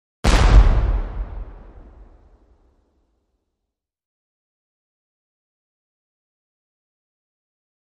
Civil War Cannon Shot With Long Ring-off.